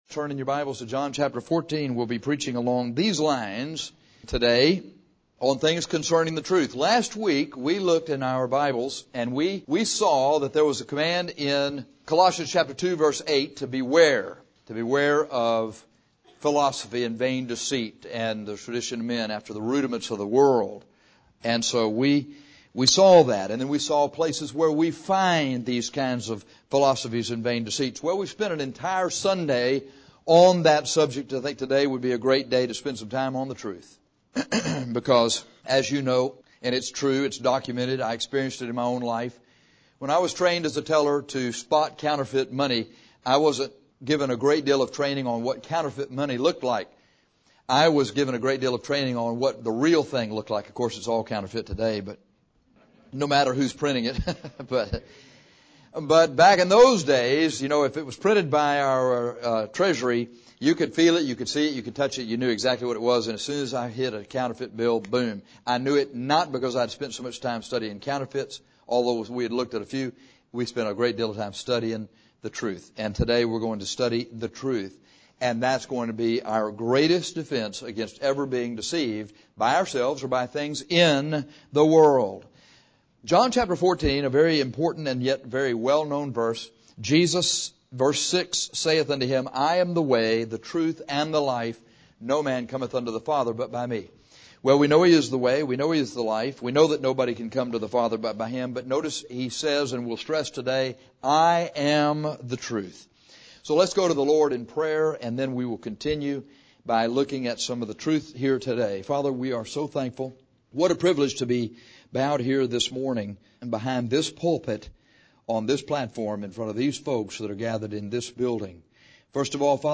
Today, in an effort to show you how to avoid these things, we are going to preach about the truth.